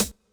hihat01.wav